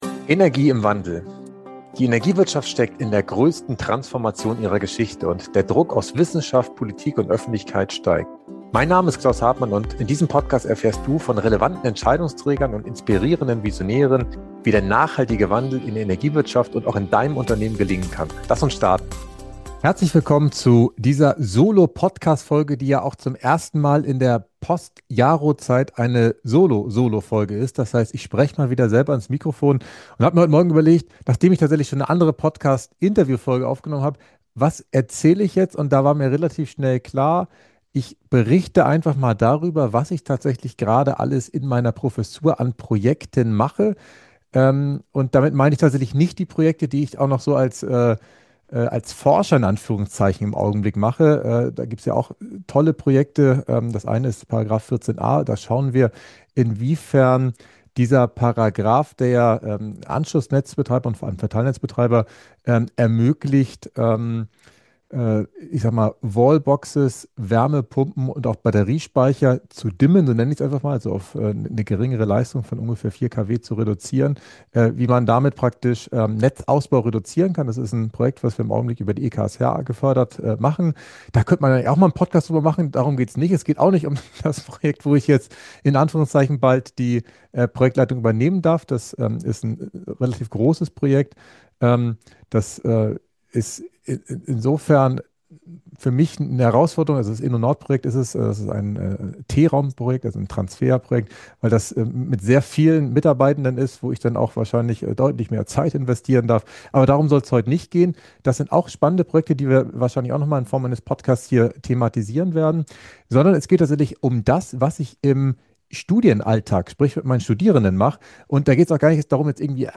In dieser persönlichen Solo-Folge nehme ich dich mit in meinen Studienalltag – dorthin, wo die Energiewende nicht nur diskutiert, sondern aktiv erlebt und gestaltet wird.